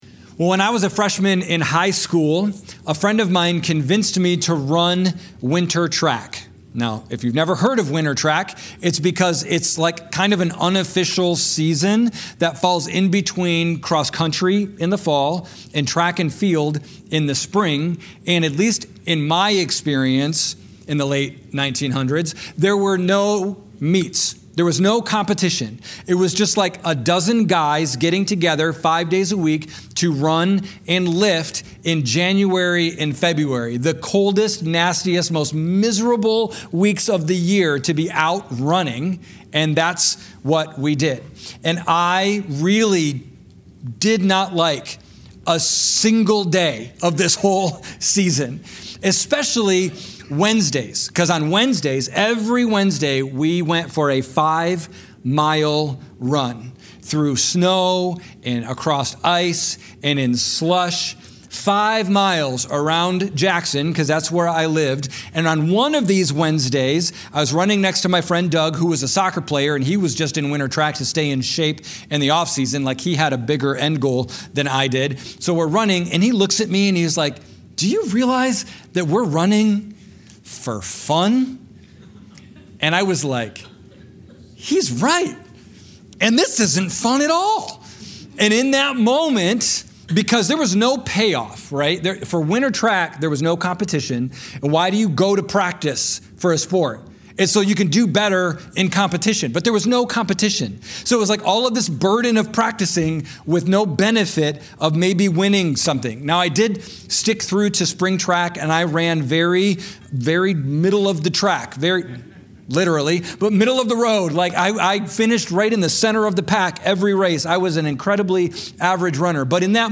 This sermon takes us into the garden, when God says no to his only begotten son, AND where Jesus shows us what bearing burdens with holy confidence looks like.